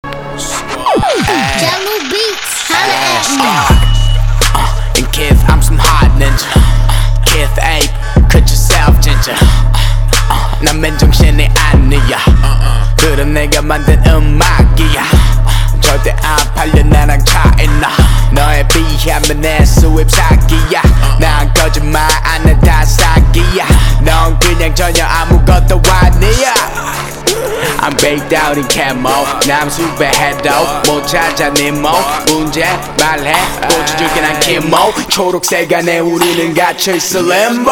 • Качество: 320, Stereo
крутые
Trap
качающие
Bass